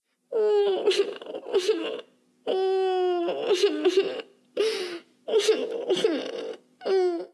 Pucheros de una niña
Sonidos: Acciones humanas
Sonidos: Voz humana